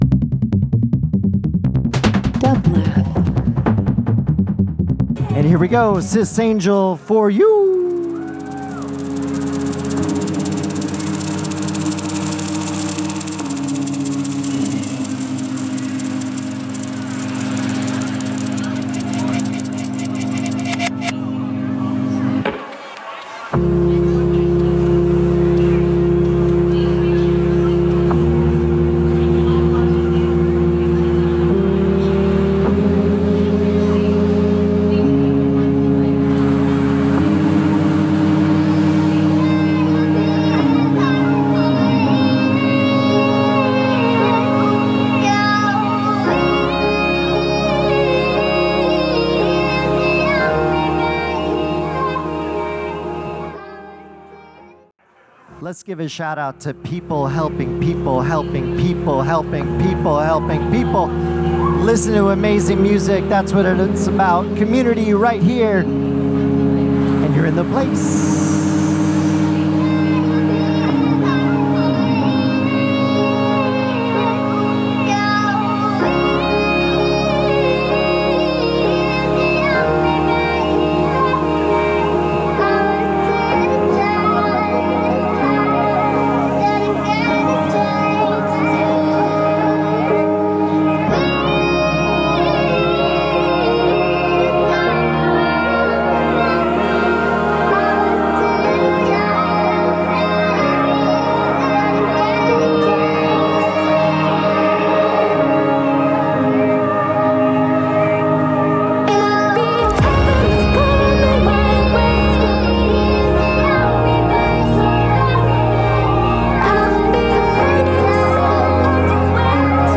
LIVE FROM THE LOFT @ PORTER STREET STUDIO – OCT 11, 2025
Electronic hyperpop Trip Hop